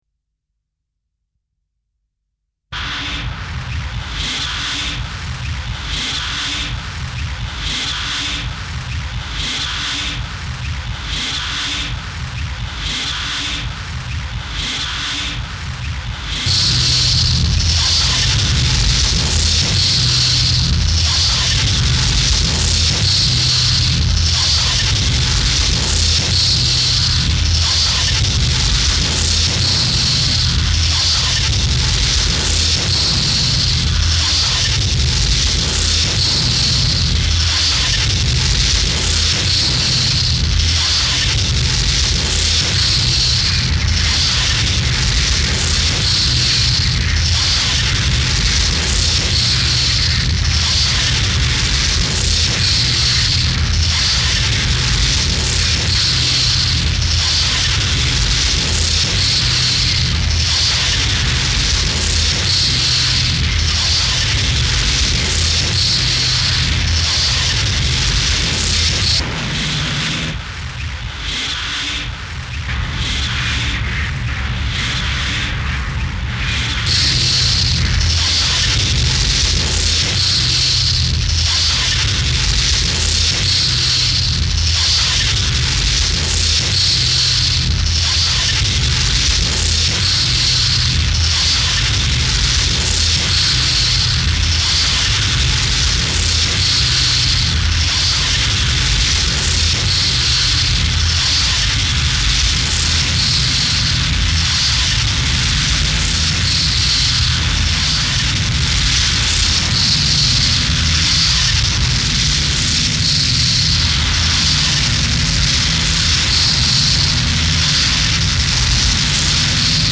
Power electronics